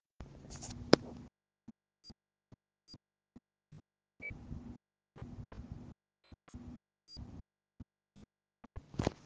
PC Neubau: GPU macht komische Geräusche
Habe hier das Geräusch.